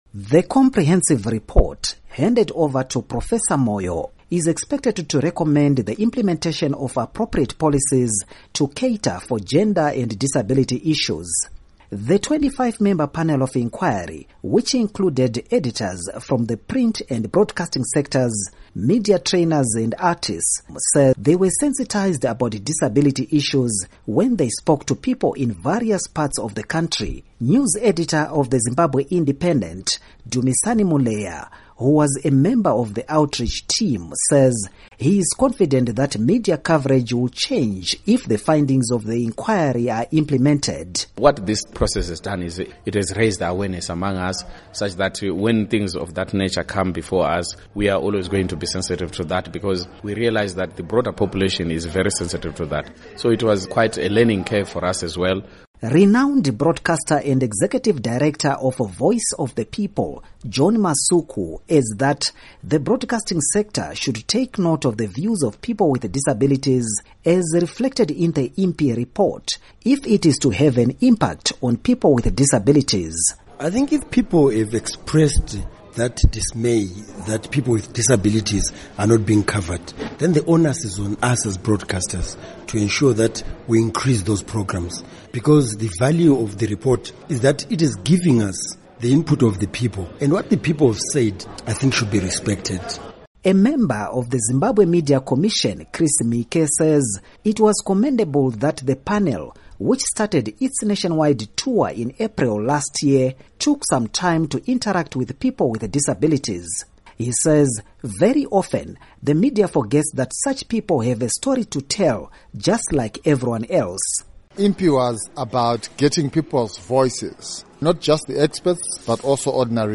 Report on Media Inquiry Findings